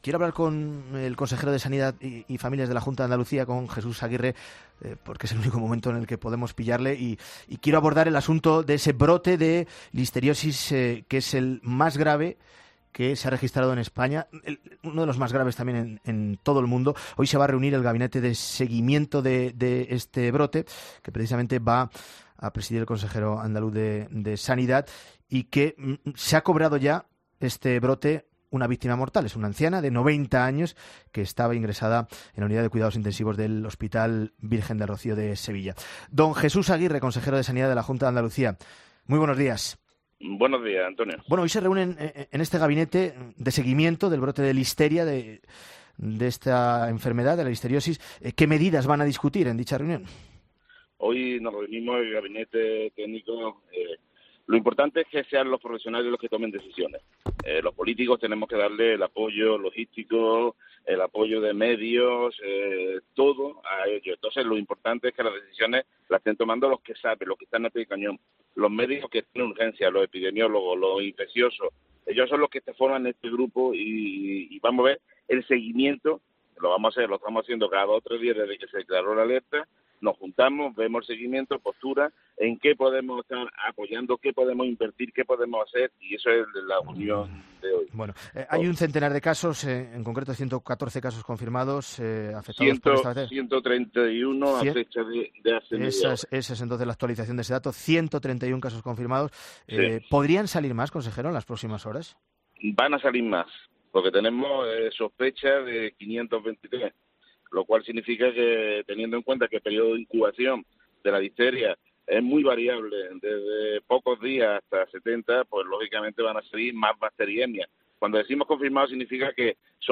En una entrevista este miércoles en 'Herrera en COPE', Aguirre ha admitido que tal vez pudo haberse detectado el origen del brote más rápido, pero que se ha actuado "con celeridad" una vez se concretó que el foco contaminante procedía de los productos de la marca "La Mechá".